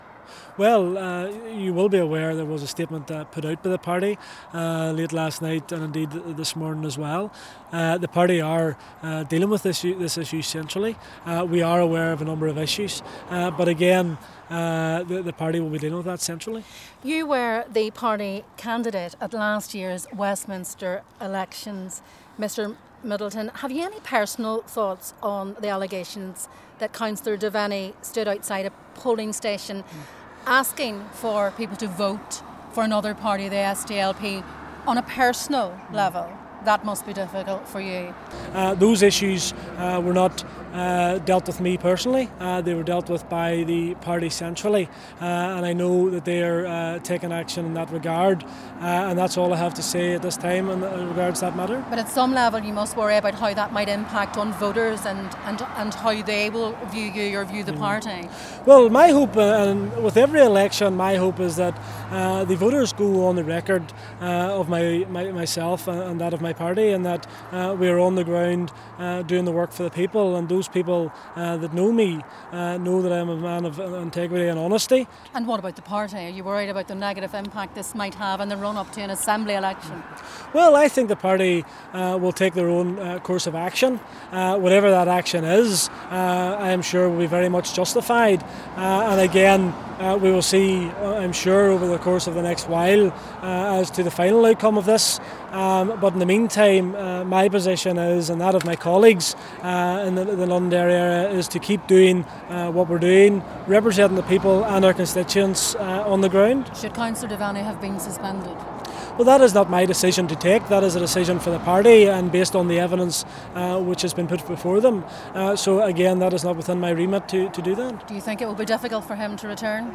LISTEN: DUP MLA Gary Middleton reacts to the suspension of colleague Maurice Devenney